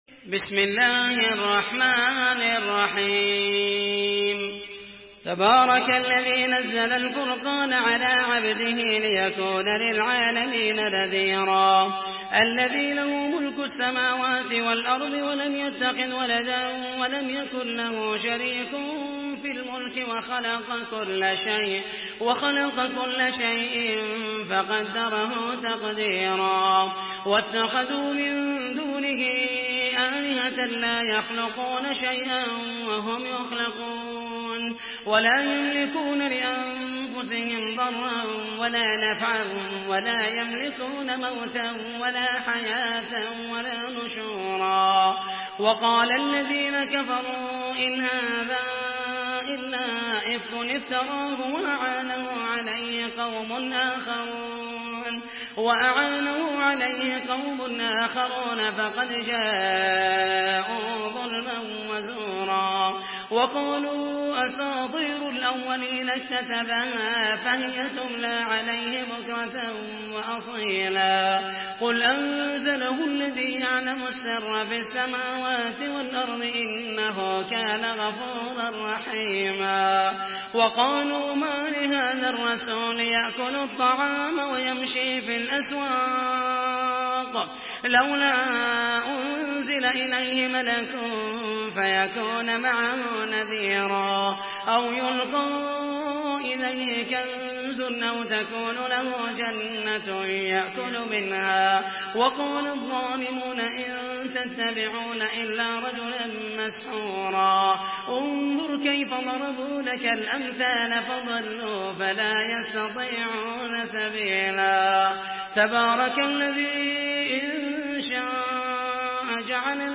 Surah Furkan MP3 by Muhammed al Mohaisany in Hafs An Asim narration.
Murattal